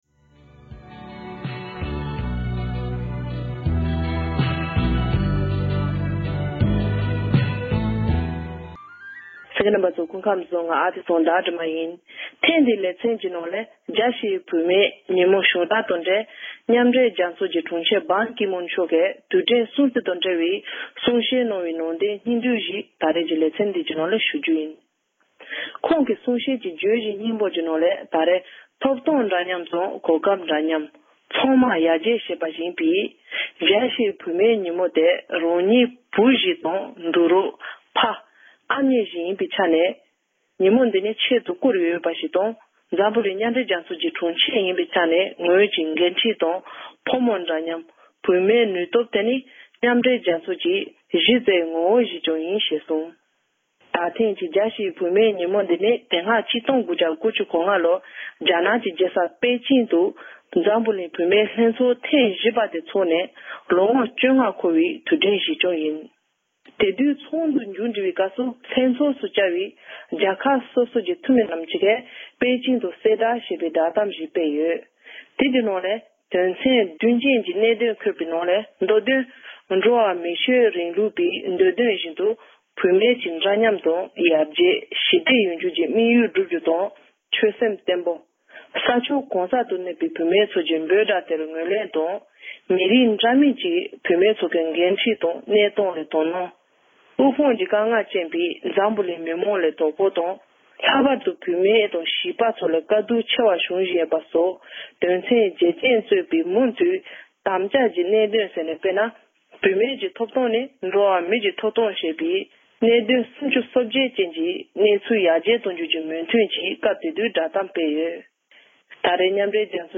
མཉམ་འབྲེལ་རྒྱལ་ཚོཊ་ཀྱི་དྲུང་ཆེ་བེན་ཀི་མོན་མཆོག་གིས་རྒྱལ་སྤྱིའི་བུད་མེད་ཀྱི་ཉིན་མོར་གསུང་བཤད་གནང་ཡོད་པ།